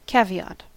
Ääntäminen
IPA: /ˈvaʁnʊŋ/ IPA: [ˈvaɐ̯nʊŋ]